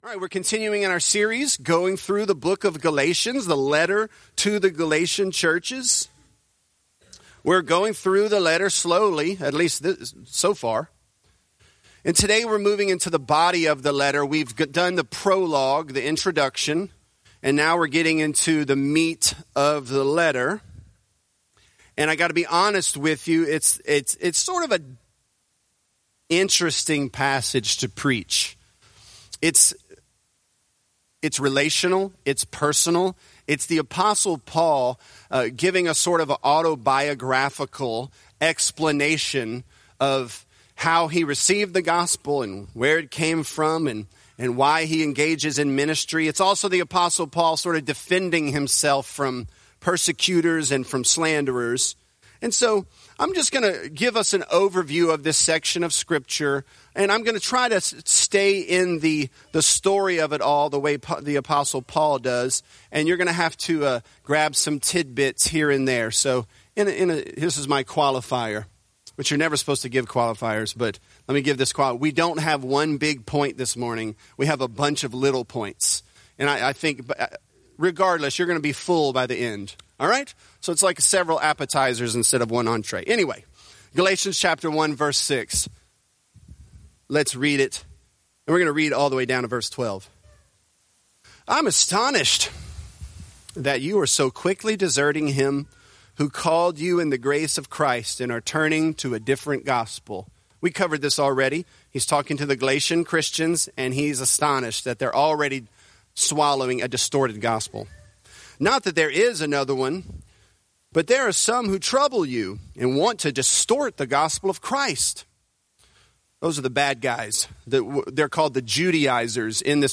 Galatians: Man-Pleasing | Lafayette - Sermon (Galatians 1)